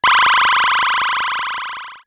ihob/Assets/Extensions/RetroGamesSoundFX/Alert/Alert06.wav at master
Alert06.wav